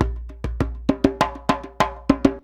100DJEMB22.wav